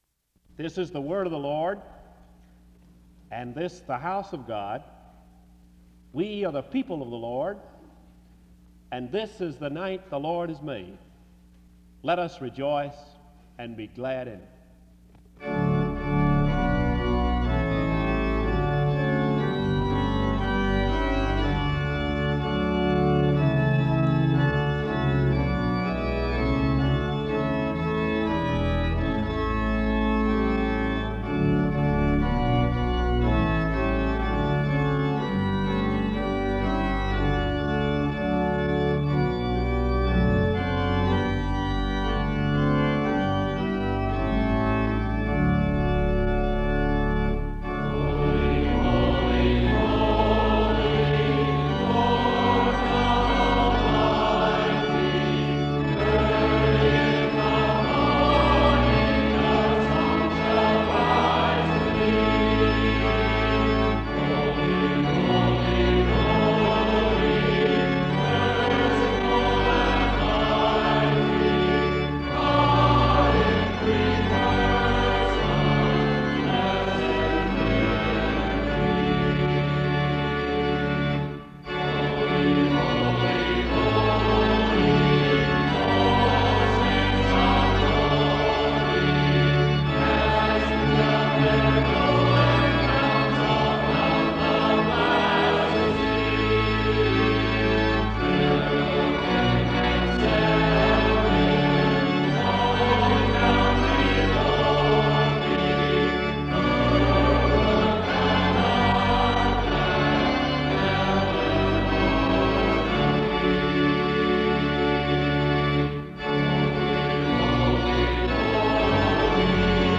He preaches on living by the grace of God. A moment of Scripture reading takes place from 16:22-18:55. Music plays from 19:17-25:16.